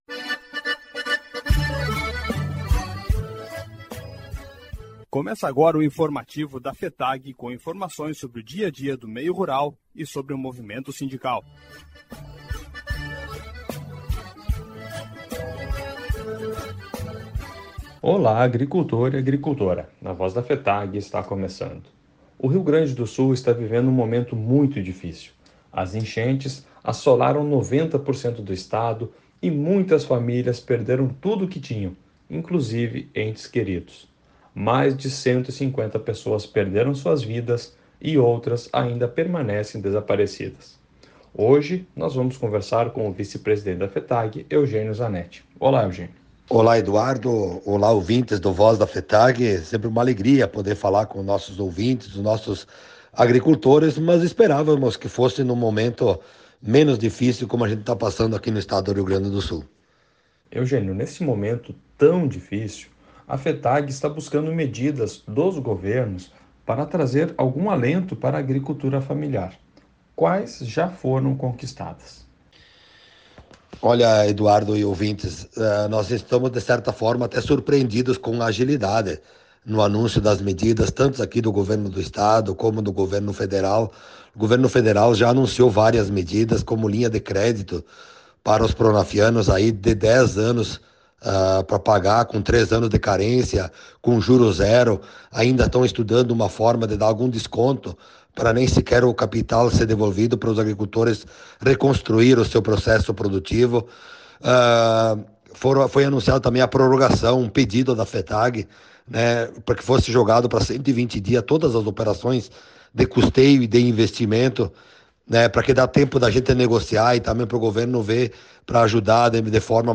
Programa de Rádio A Voz da FETAG-RS